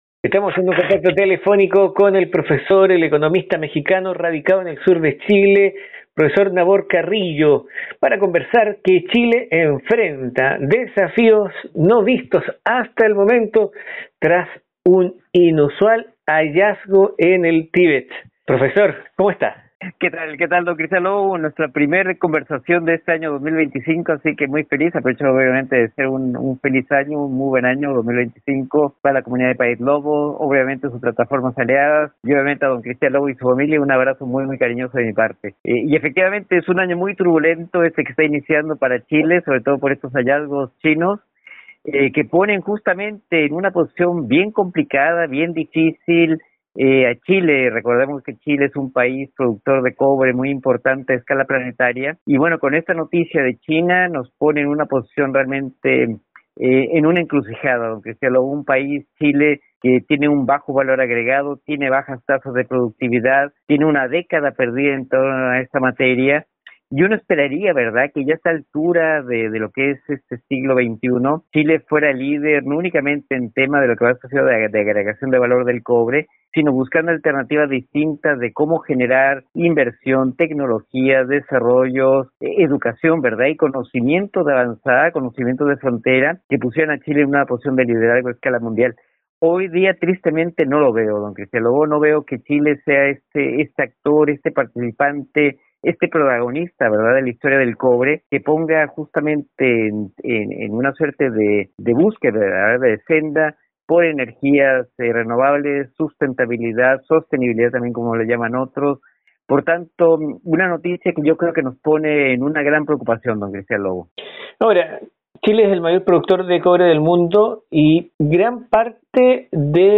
El reciente anuncio de un hallazgo de cobre en el Tíbet ha generado una ola de preocupación en Chile, el mayor productor de este mineral a nivel mundial. Durante una entrevista con Paíslobo